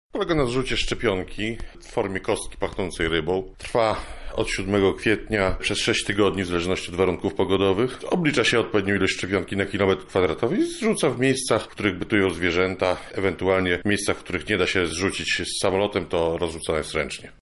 O szczegółach akcji mówi Paweł Piotrowski, Lubelski Wojewódzki Lekarz Weterynarii: